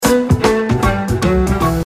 هشدار پیامک